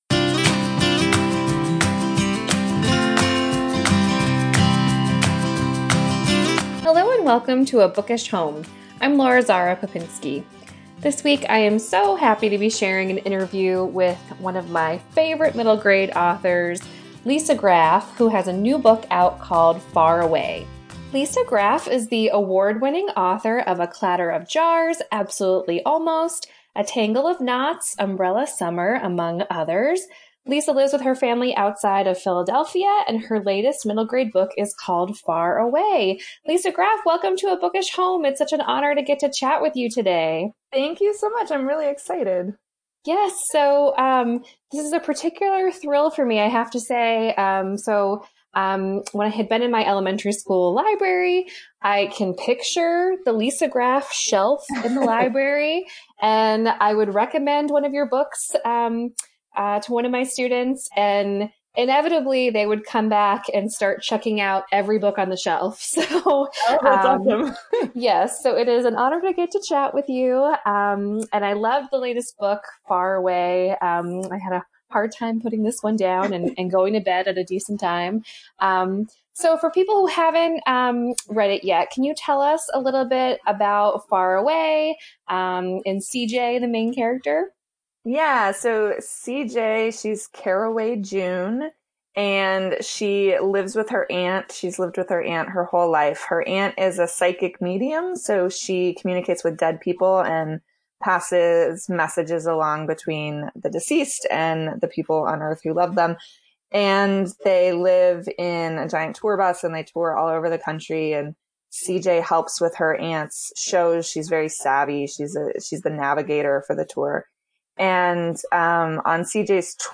This week I’m sharing an interview with the wonderful middle grade author